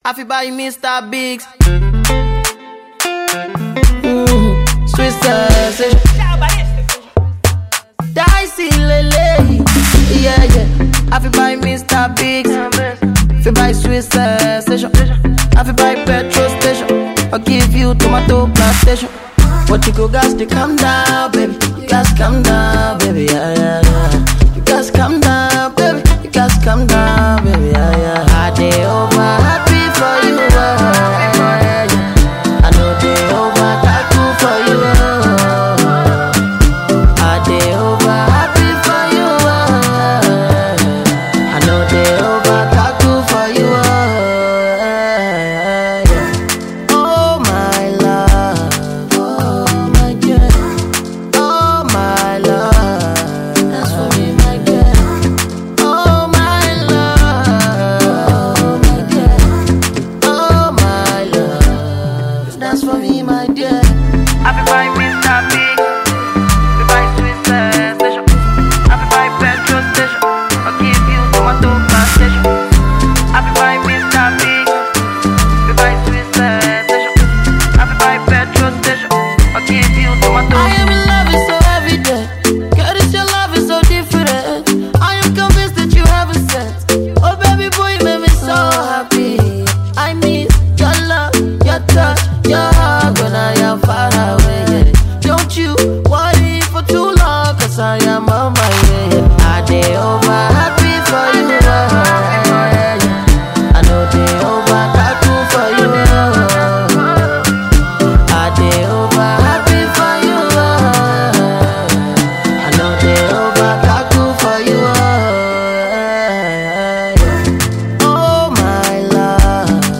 excellent pop infused beat